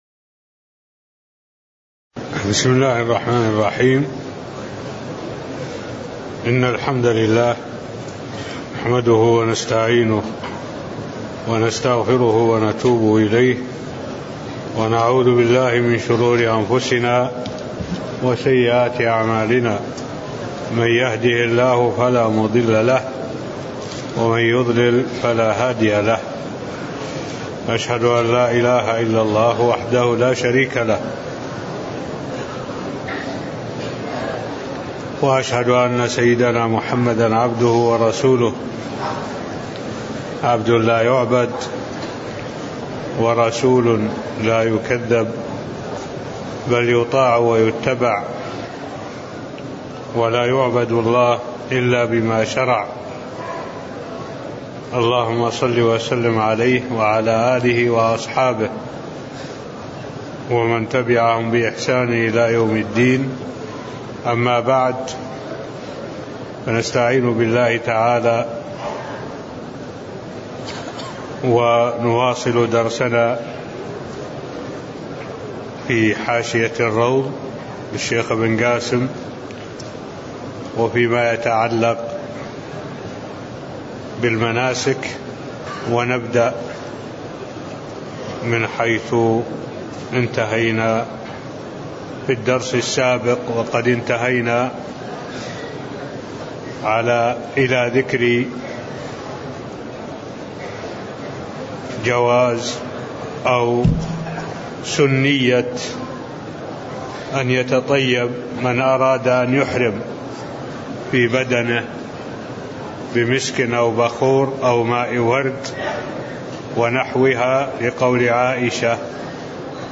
المكان: المسجد النبوي الشيخ: معالي الشيخ الدكتور صالح بن عبد الله العبود معالي الشيخ الدكتور صالح بن عبد الله العبود سنية أن يتطيب من أراد أن يحرم (02)